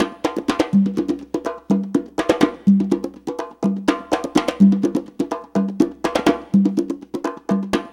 CONGA BEAT44.wav